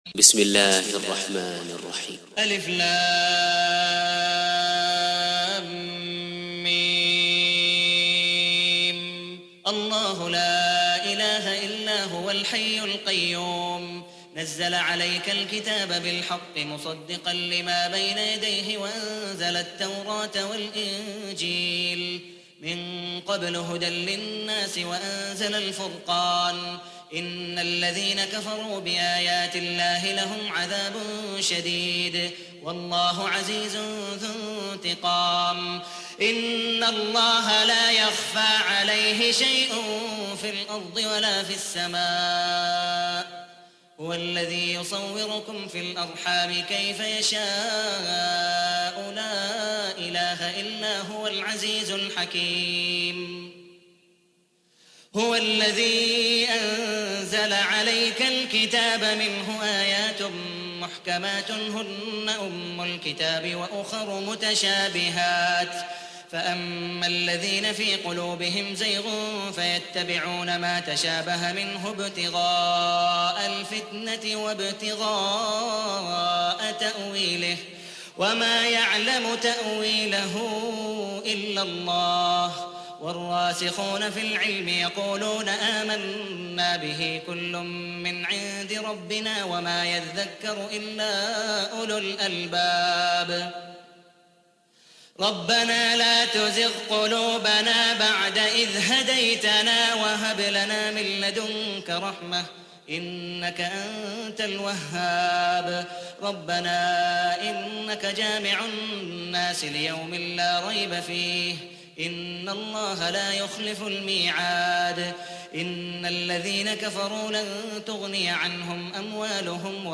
تحميل : 3. سورة آل عمران / القارئ عبد الودود مقبول حنيف / القرآن الكريم / موقع يا حسين